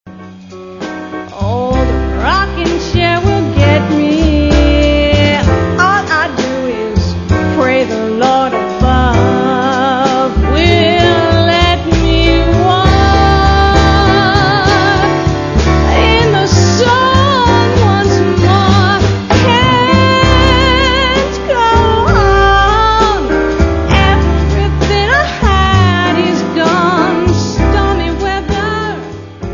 Vocals
Piano
Double Bass
Drums